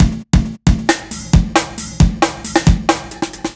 Funky Break 3 135.wav